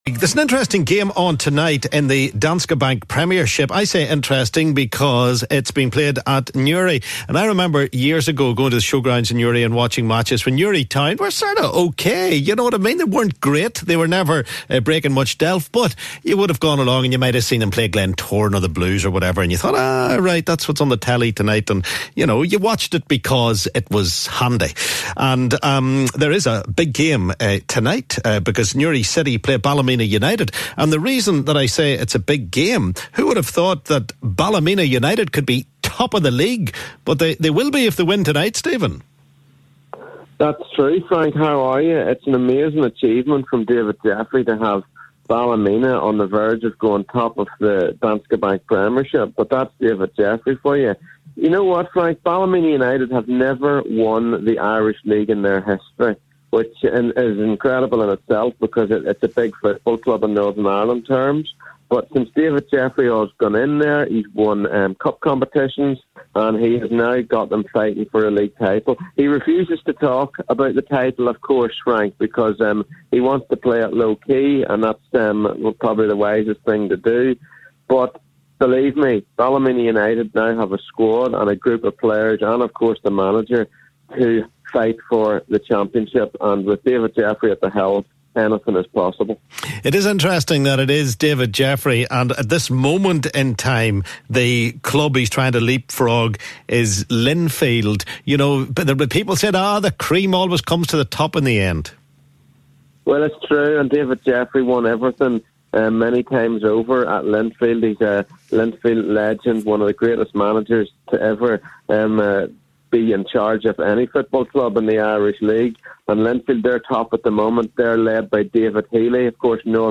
talks to sports reporter